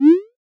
Text-Message or Videogame-Jump
8bit App Button clean elegant Game Jump Message sound effect free sound royalty free Sound Effects